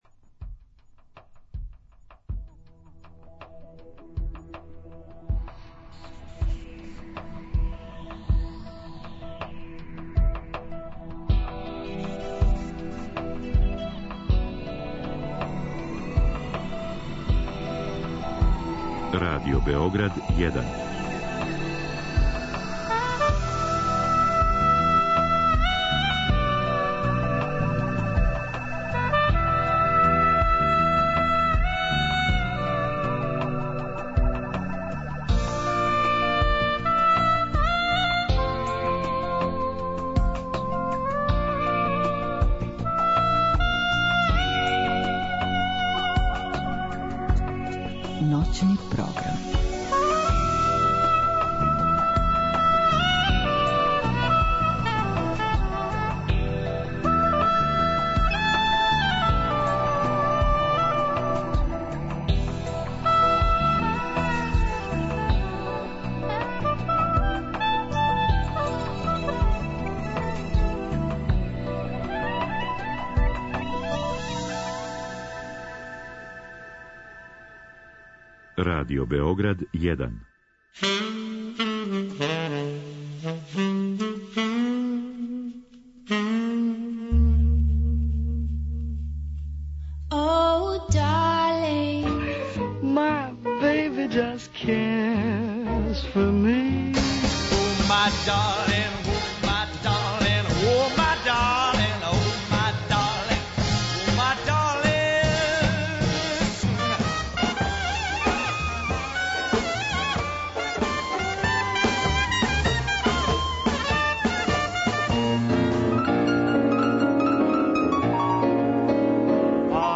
Џез стандарди, популарне композиције у џез аранжманима, музика из продукције Радио Београда и концертни снимци биће тематски подељени у 4 сата.
Други сат је посвећен вокално-инструменталним џез групама.